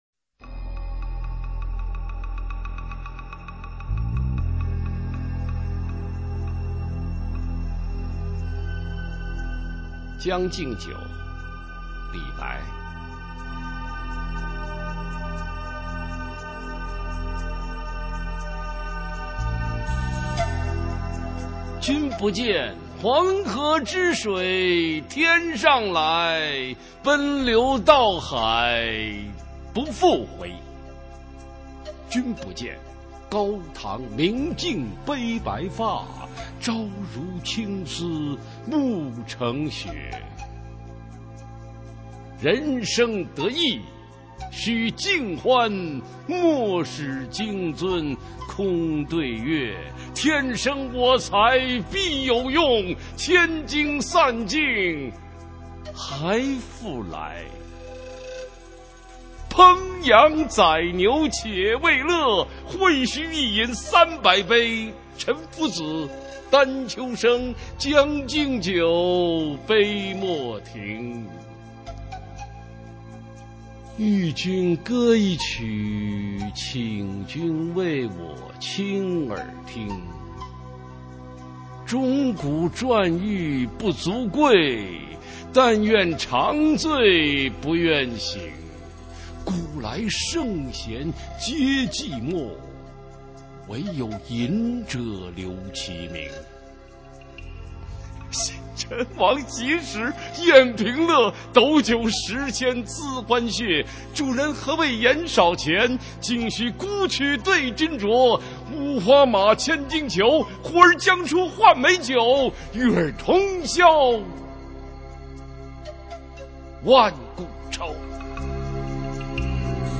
普通话美声欣赏：将进酒